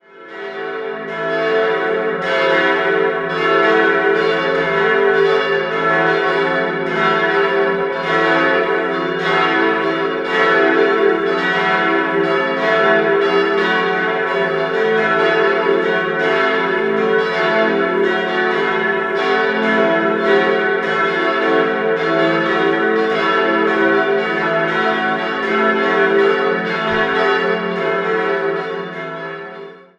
Dieser wurde erst 1973/74 ergänzt. 4-stimmiges Geläut: e'-fis'-a'-cis'' Die Glocken wurden 1973 von Perner in Passau gegossen.